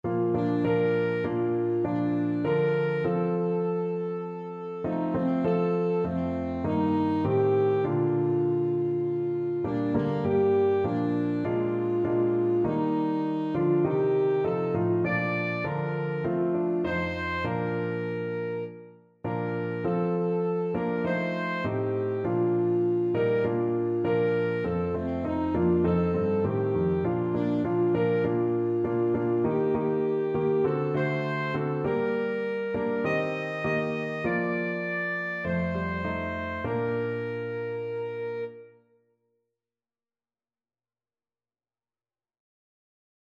Alto Saxophone
4/4 (View more 4/4 Music)
Con Spirito